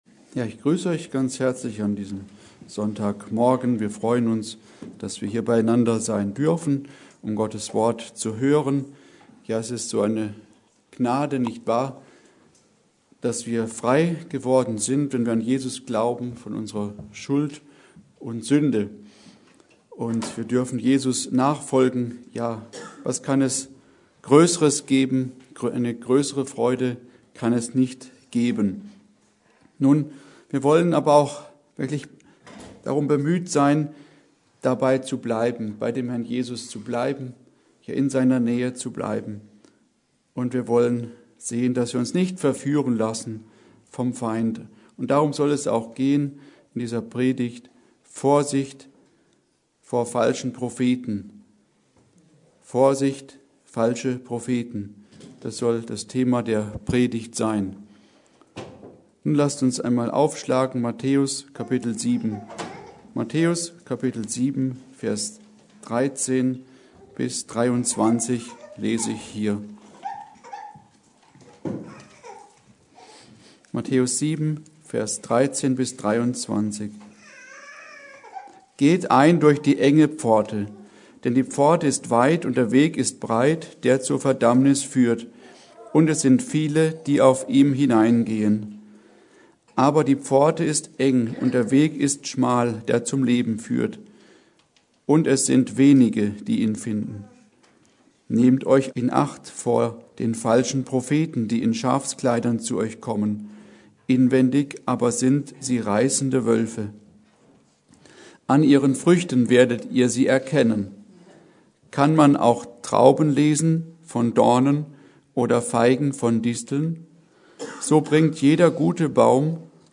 Predigt: Vorsicht, falsche Propheten!
Serie: Gottesdienste Wegbereiter-Missionsgemeinde Passage: Matthäus 7, 13-23 Kategorie